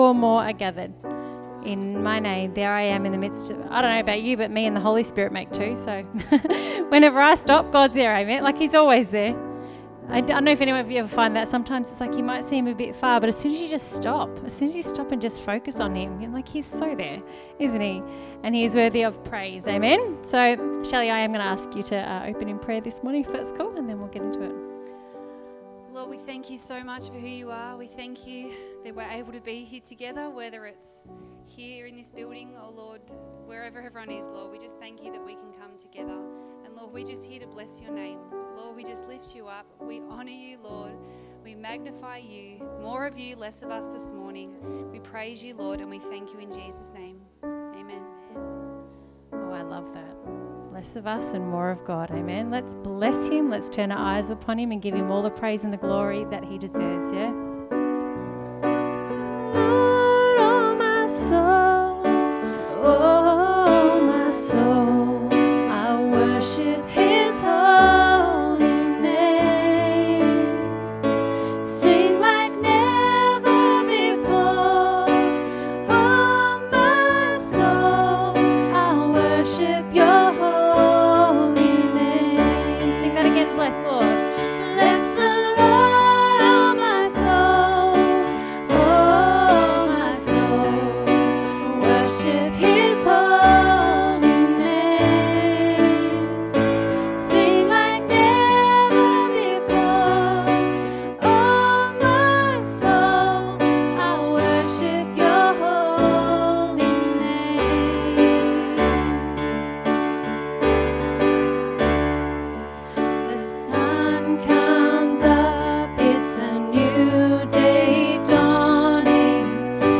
Sunday Service 2nd August 2020